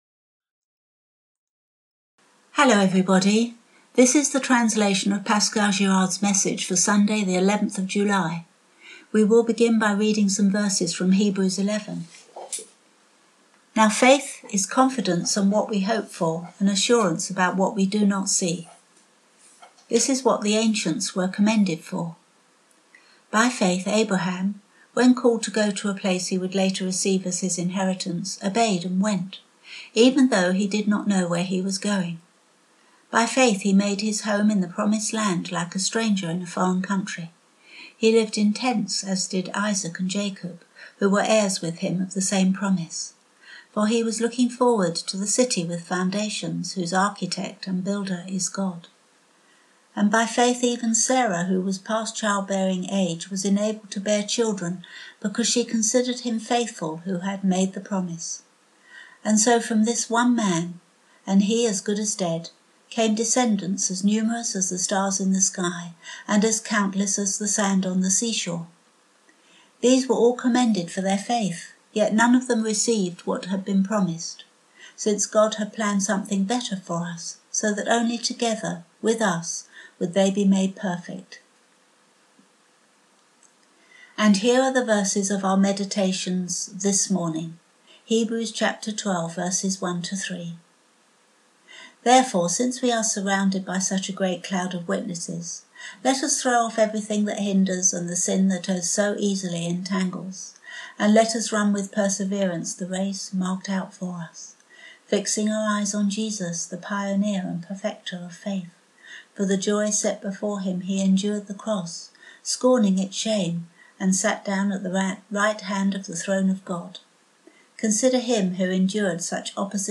Sunday sermons Archives - Page 13 of 24 - FREE EVANGELICAL CHURCH BERGERAC